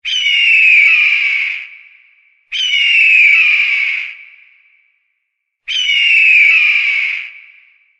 Tiếng chim Ưng kêu mp3